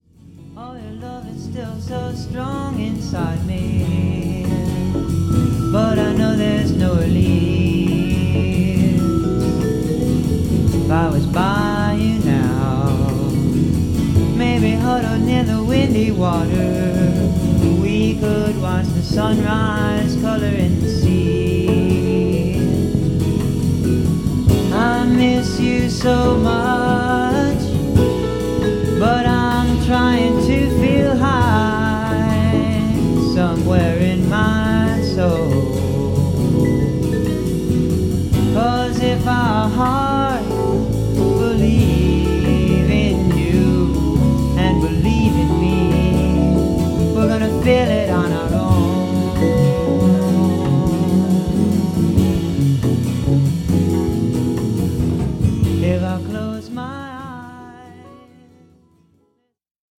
NYのS&SW/ギタリスト/ピアニスト